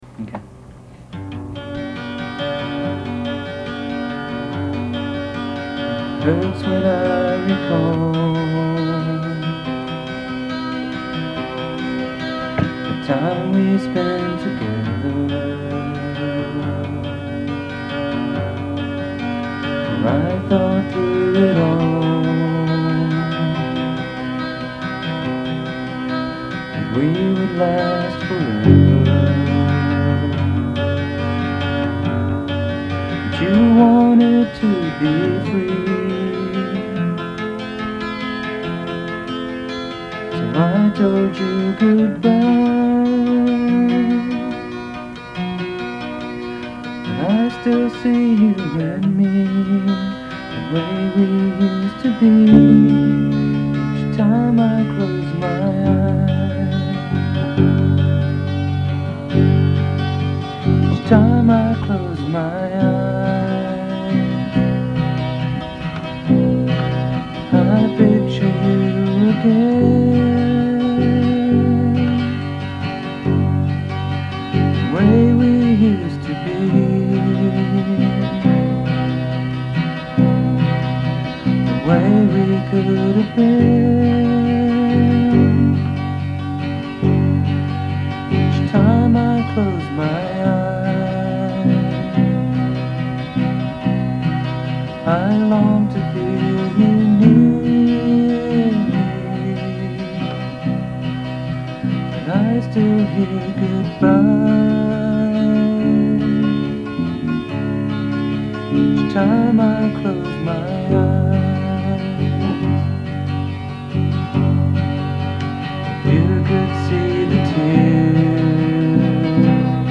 The link below is for an original demo that was recorded with a couple of friends.
guitar
bass